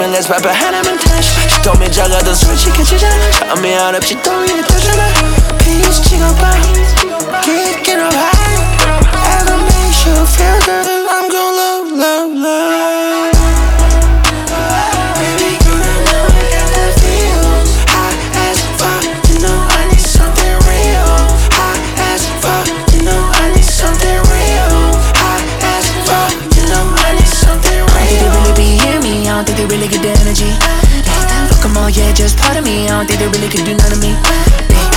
Korean Hip-Hop
Жанр: Хип-Хоп / Рэп / Поп музыка